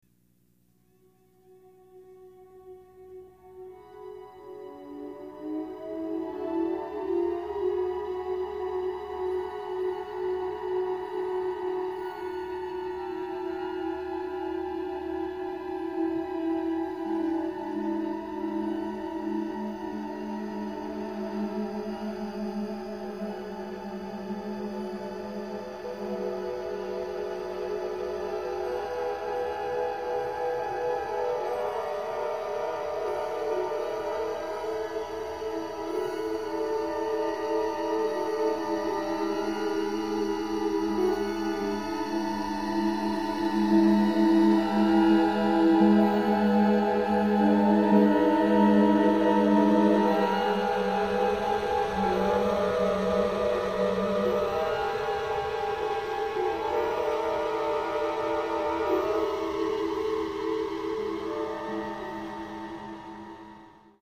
H A R M O N I E S (electronics)
A principle interval found throughout the piece is the descending major second. The microtonal changes in pitch between various source tracks produce a rich harmonic texture which thins as the piece proceeds, resulting in only the basic elements being audible near the final cadence.
The contrapuntal voices result in verticalizations which produce progressions.
The piece was conceived and composed at Northwestern University Electronic Music Studios (the Moog Studio) using both analog and digital recording, synthesizing and sampling equipment.